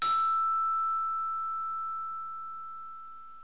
chime1.wav